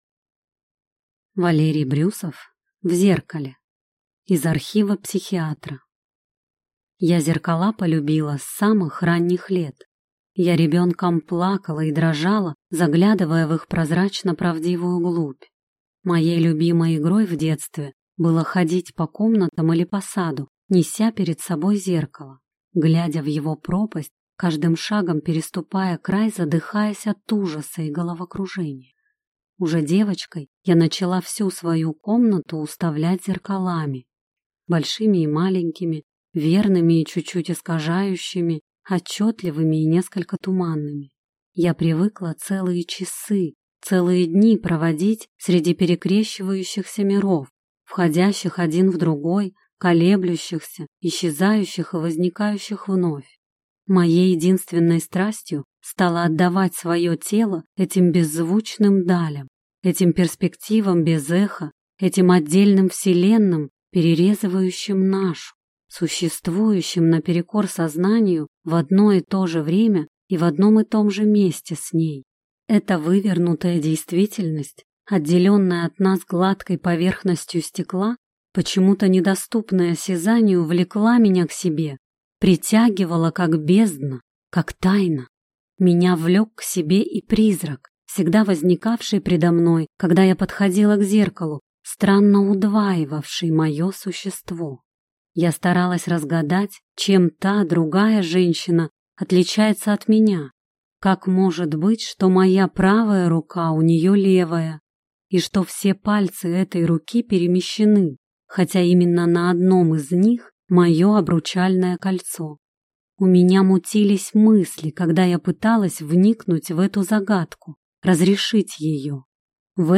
Аудиокнига В зеркале | Библиотека аудиокниг